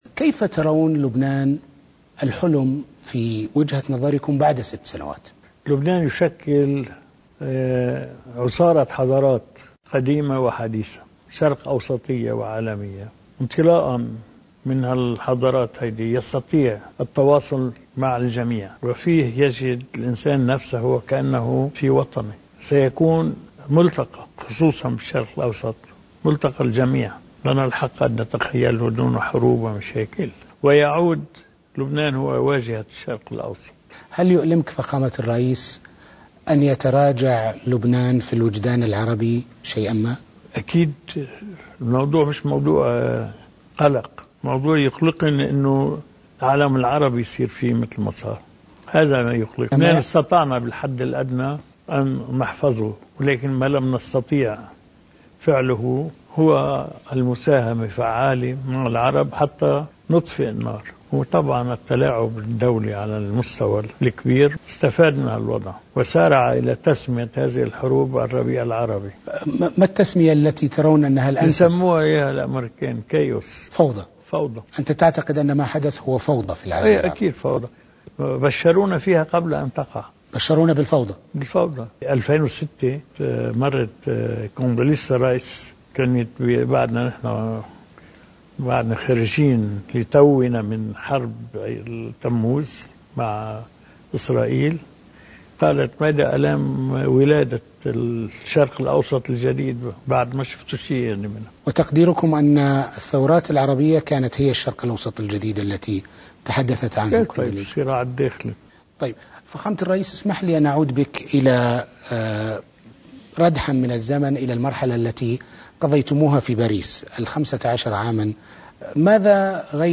مقتطف من حديث الرئيس عون قناة العربية، مع الإعلامي تركي الدخيل: